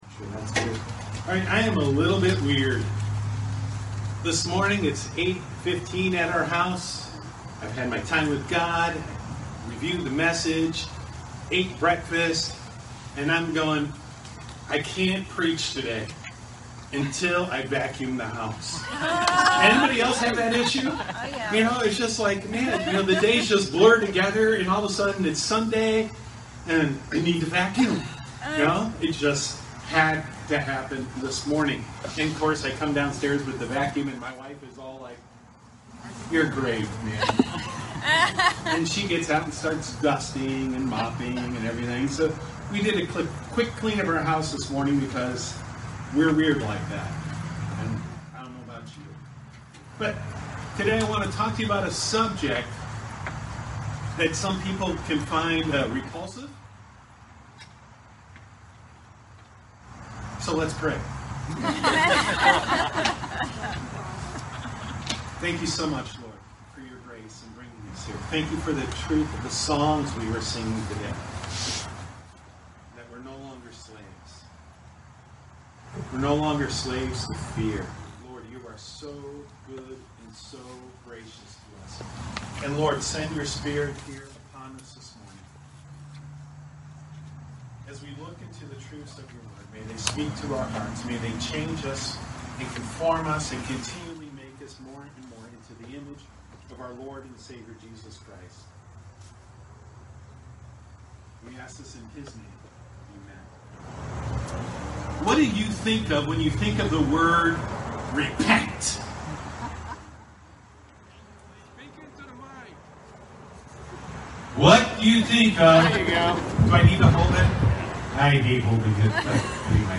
preaches about repentance